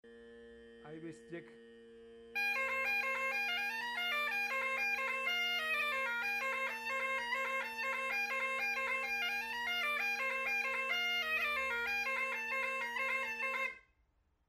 Galician music, bagpipe, gaita, percussion instruments, musical genres, Celticity